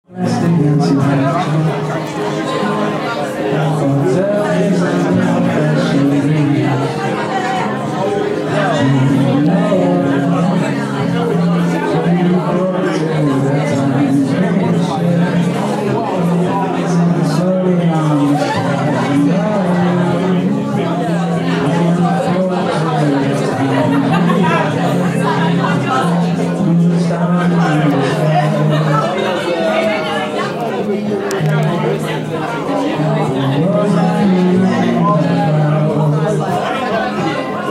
Gig talking
seriously, how much music can you hear over the talking?